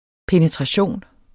Udtale [ penətʁɑˈɕoˀn ]